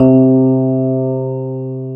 Index of /90_sSampleCDs/Syntec - Wall of Sounds VOL-2/JV-1080/SMALL-PIANO